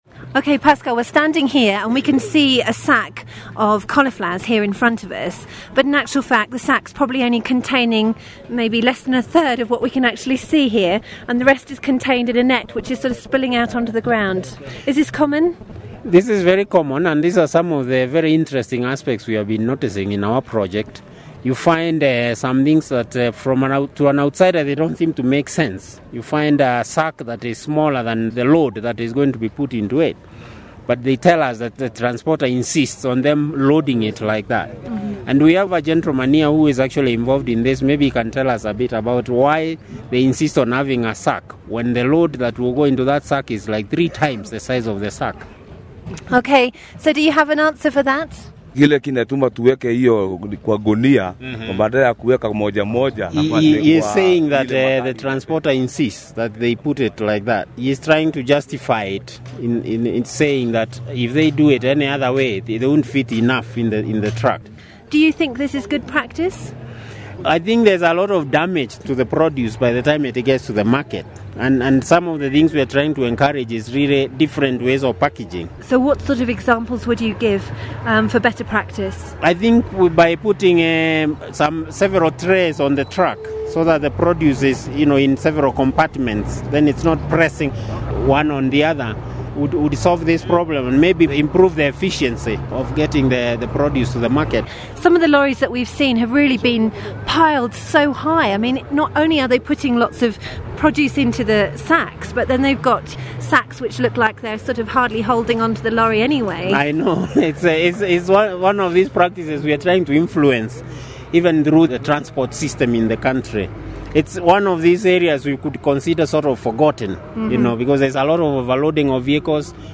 Interview and sound file from WRENmedia